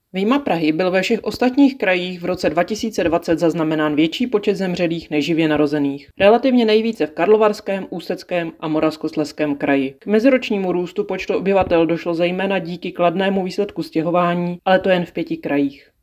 Vyjádření Evy Krumpové, 1. místopředsedkyně ČSÚ, soubor ve formátu WAV, 2.7 MB